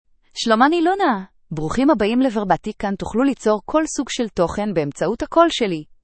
Luna — Female Hebrew (Israel) AI Voice | TTS, Voice Cloning & Video | Verbatik AI
Luna is a female AI voice for Hebrew (Israel).
Voice sample
Listen to Luna's female Hebrew voice.
Female
Luna delivers clear pronunciation with authentic Israel Hebrew intonation, making your content sound professionally produced.